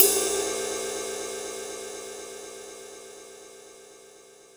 Index of /kb6/Korg_05R-W/Korg Cymbals
Ride Cym 02 X5.wav